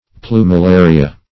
Search Result for " plumularia" : The Collaborative International Dictionary of English v.0.48: Plumularia \Plu`mu*la"ri*a\, n.; pl.
plumularia.mp3